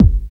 35 KICK 3.wav